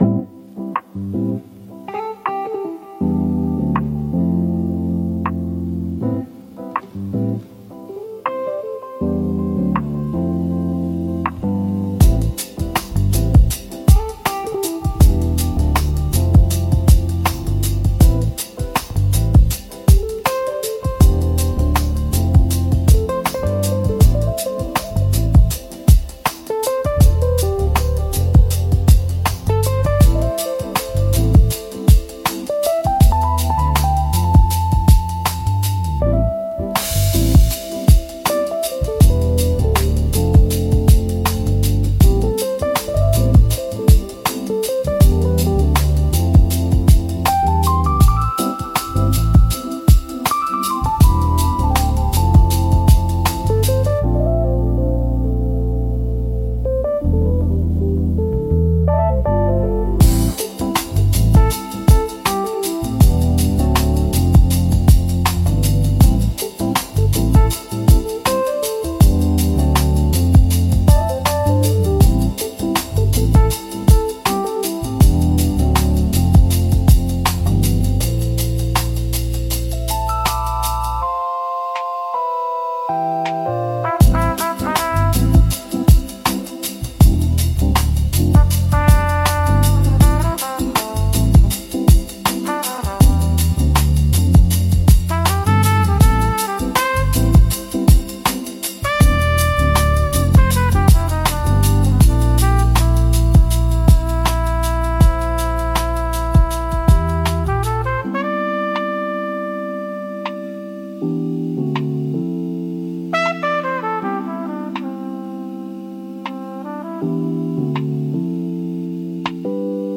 ラウンジは、洗練された落ち着いた雰囲気を持つジャンルで、ゆったりとしたテンポと滑らかなサウンドが特徴です。
ジャズやボサノバ、エレクトロニカの要素が融合し、都会的でリラックスした空間を演出します。
リラックス効果が高く、会話の邪魔をせず心地よい背景音として居心地の良さを高めます。